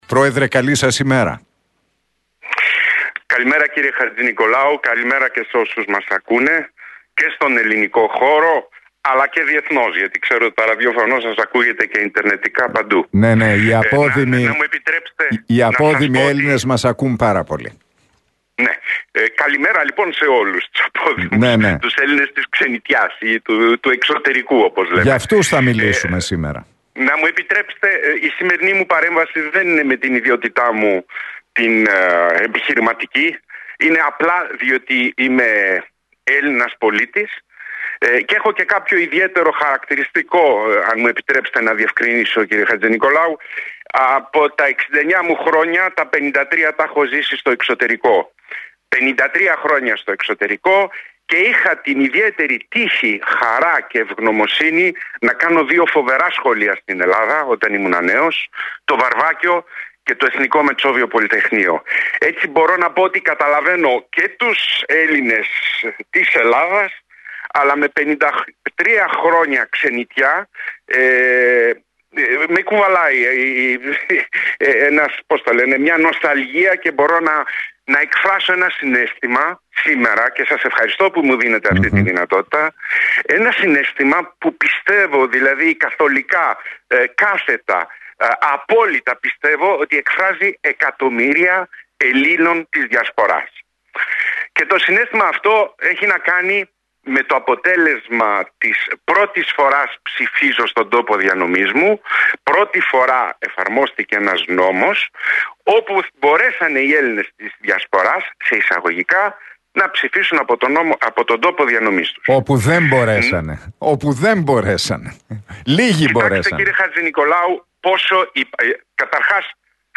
μιλώντας στην εκπομπή του Νίκου Χατζηνικολάου στον Realfm 97,8.